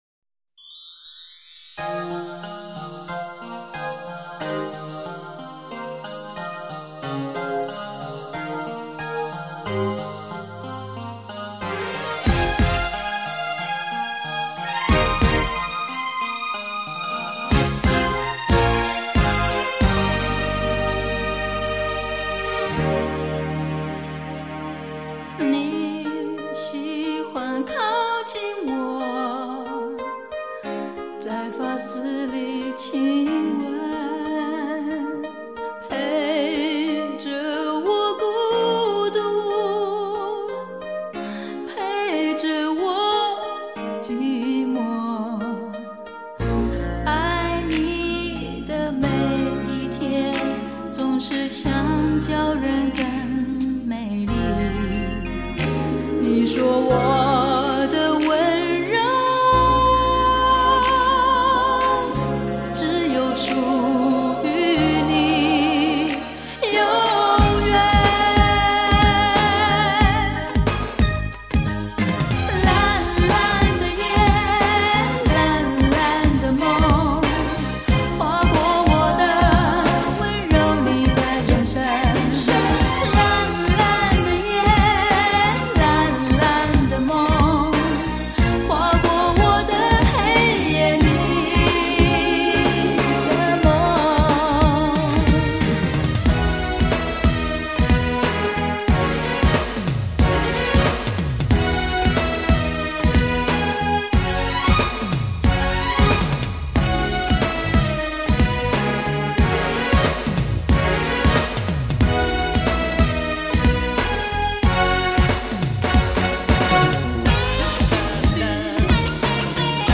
更温柔也更自在